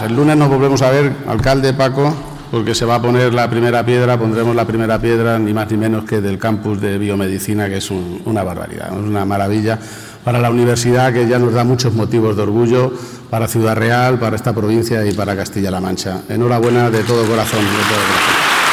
El jefe del Ejecutivo regional hacía estas declaraciones en el marco de la V edición de los Premios Cooperativos que entrega Cooperativas Agro-Alimentarias de Castilla-La Mancha y que han tenido lugar en el Paraninfo de la UCLM, en el campus de Ciudad Real.